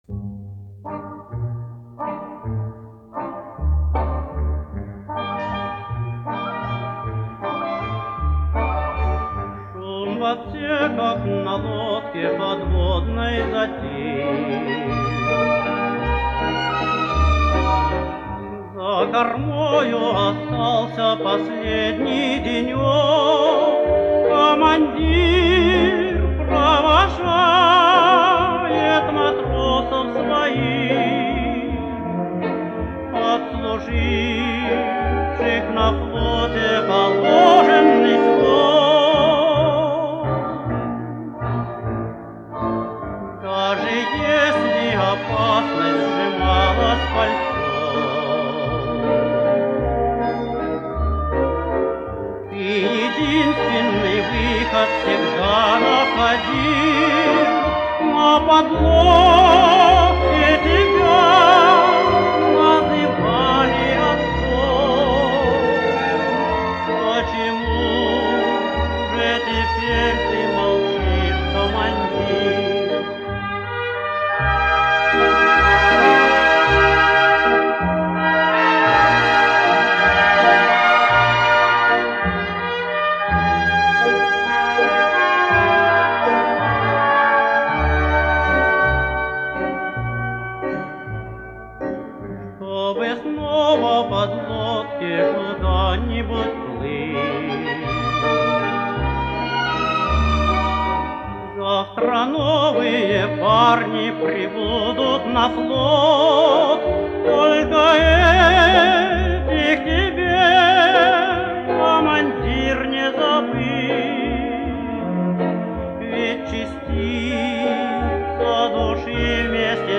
Описание: Нежная морская лирика.